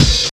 84 OP HAT.wav